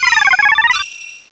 pokeemerald / sound / direct_sound_samples / cries / chingling.aif
chingling.aif